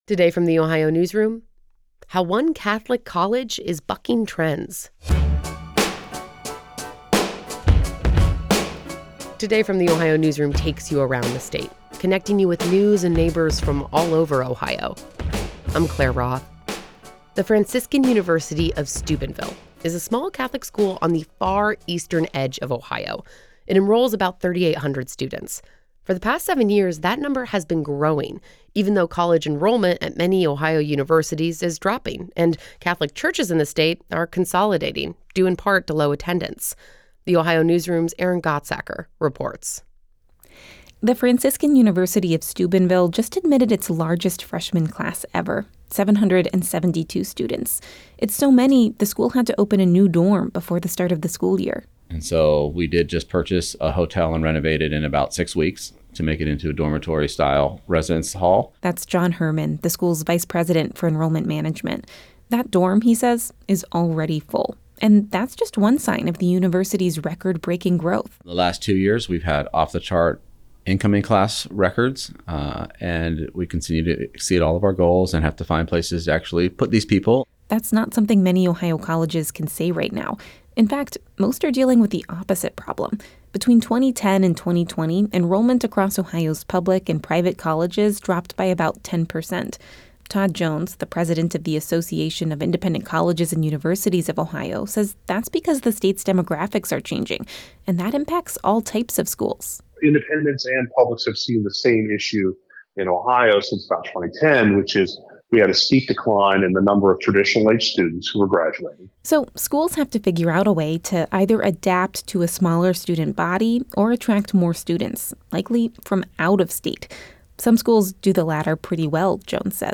“If you're going to go to confession around here, you have to have time to wait,” he whispered inside the campus church.